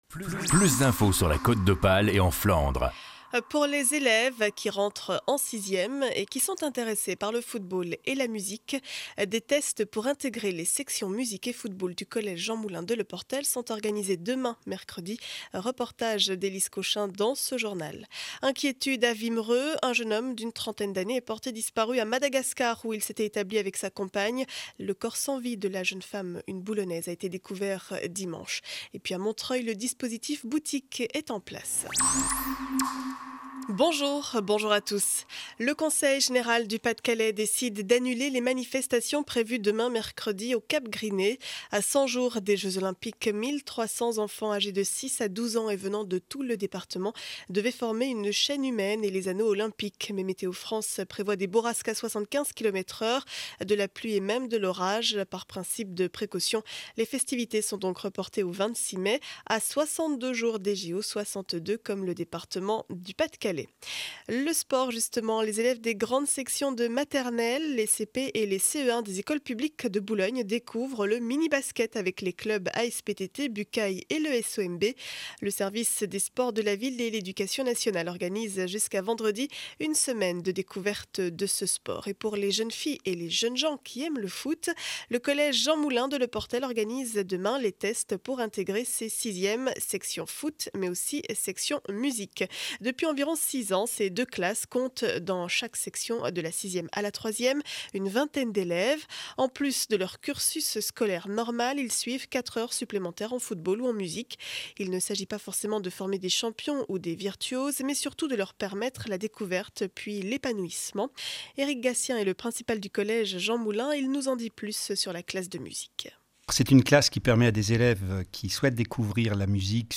Journal du mardi 17 avril 2012 7 heures 30 édition du Boulonnais.